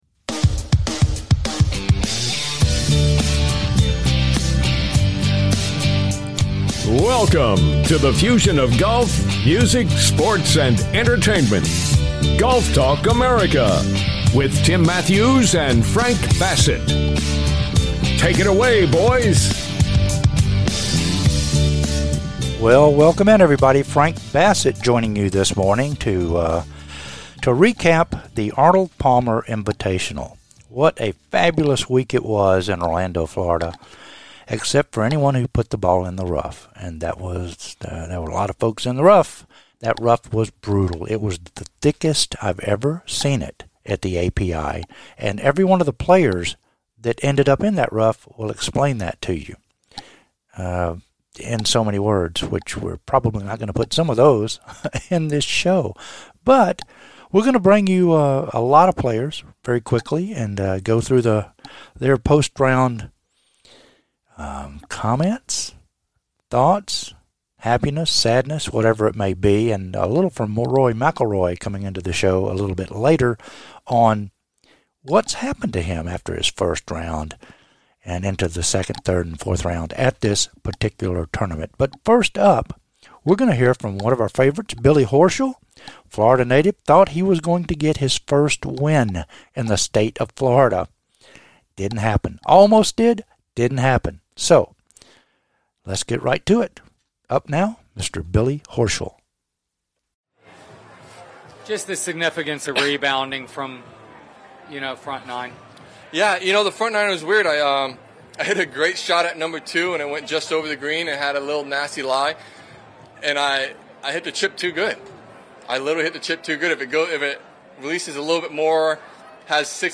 We hear from Billy, Rory, Gary, Victor and Scottie as they wrap up their API week.